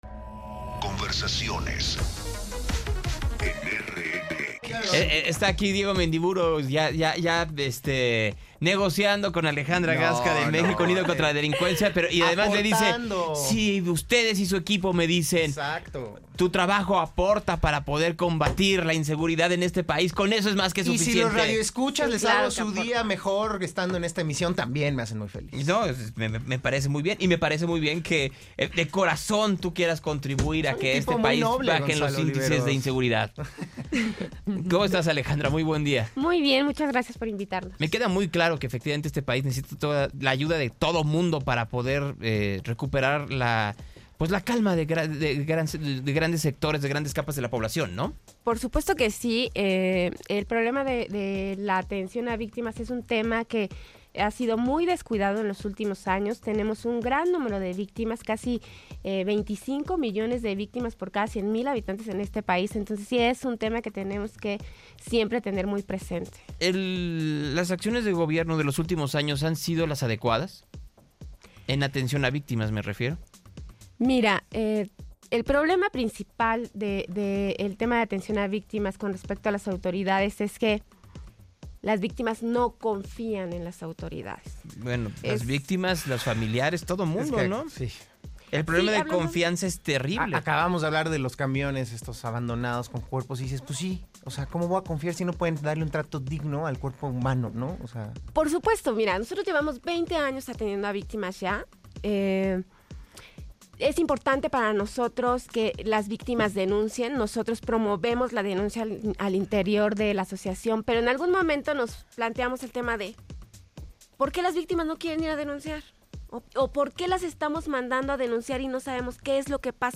En conversación